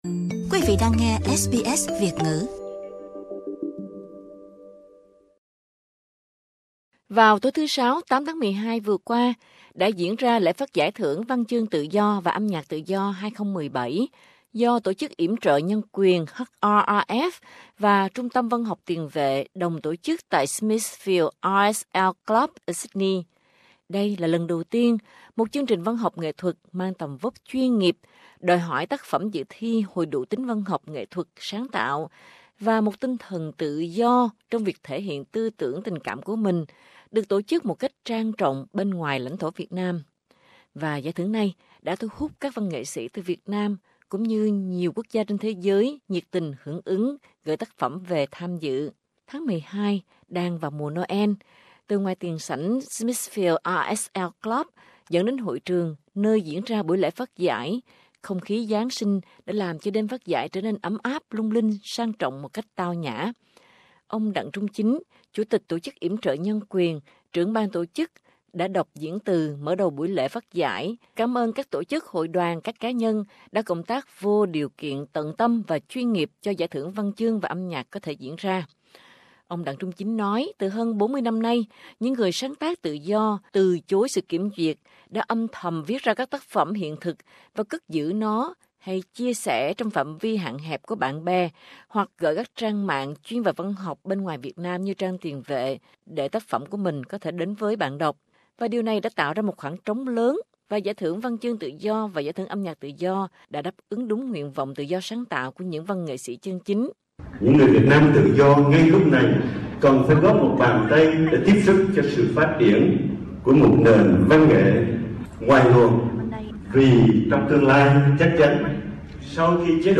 Dưới đây là bản tin (ngày 11/12/2017) của SBS Radio (Đài phát thanh đa văn hoá của Australia) tường thuật về Lễ Trao Giải Thưởng Văn Chương & Âm Nhạc Tự Do 2017.